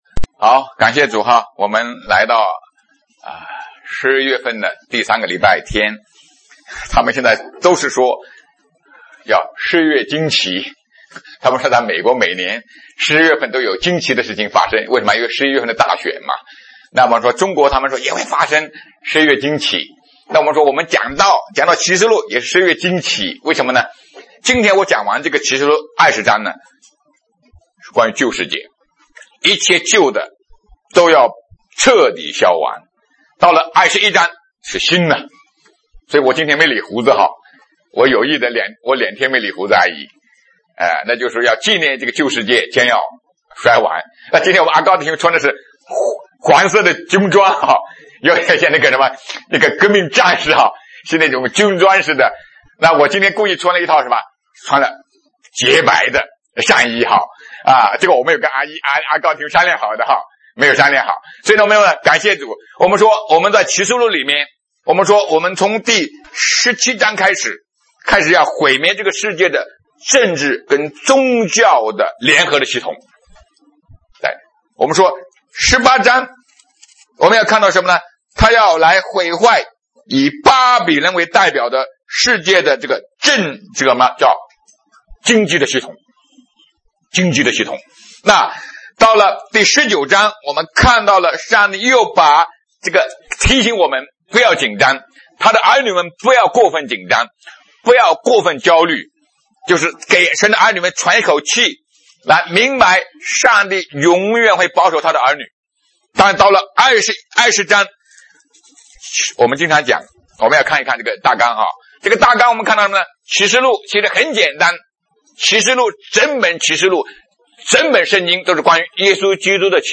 两种不同的分别 2020年10月18日 上午11:33 作者：admin 分类： 启示录圣经讲道 阅读(4.15K